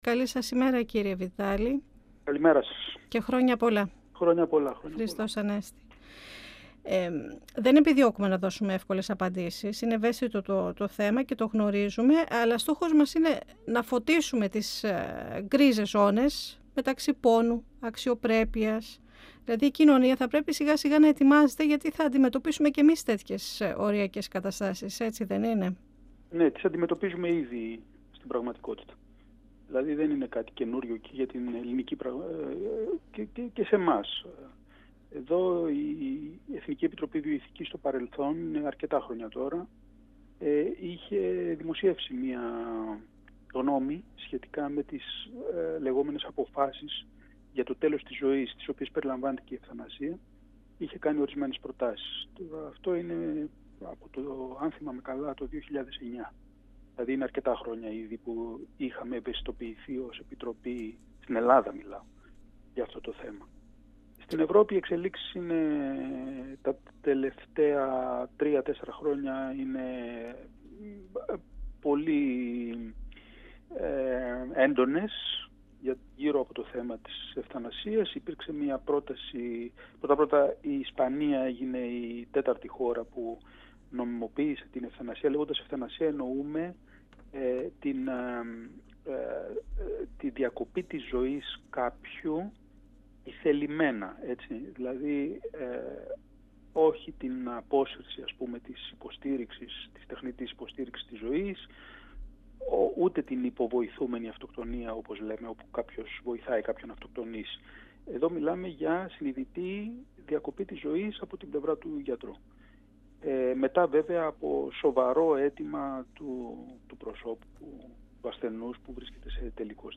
μέλος της Επιτροπής Βιοηθικής της ΕΕ. 102FM Ο Μεν και η Δε Συνεντεύξεις ΕΡΤ3